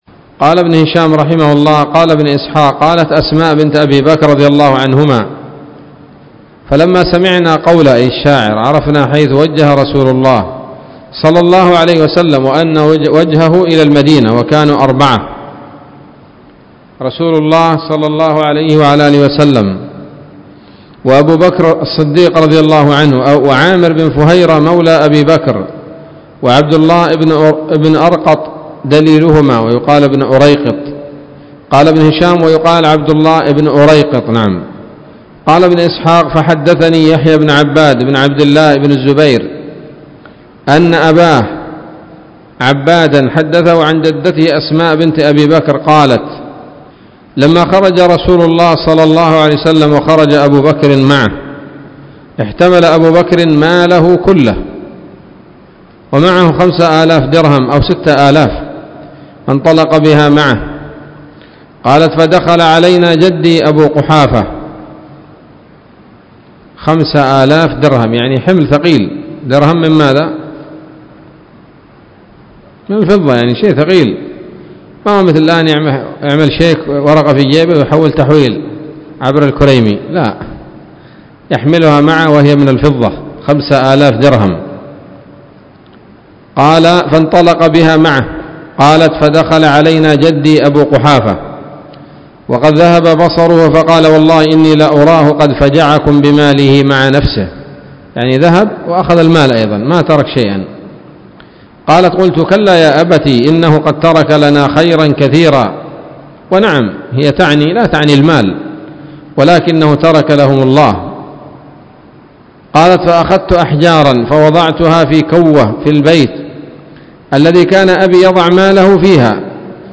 الدرس الرابع والسبعون من التعليق على كتاب السيرة النبوية لابن هشام